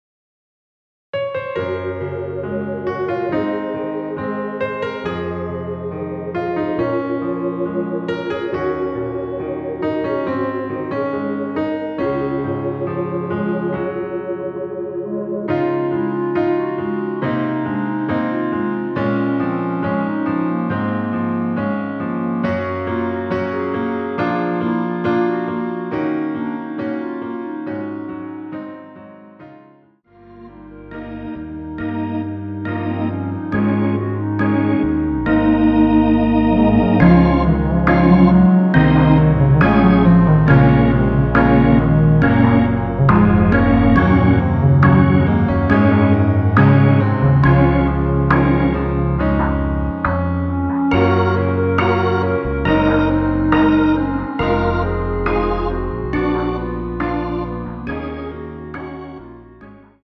중간 간주 부분이 길어서 짧게 편곡 하였습니다.(미리듣기 확인)
원키에서(-1)내린 멜로디 포함된 간주 짧게 편곡한 MR입니다.
Db
앞부분30초, 뒷부분30초씩 편집해서 올려 드리고 있습니다.
(멜로디 MR)은 가이드 멜로디가 포함된 MR 입니다.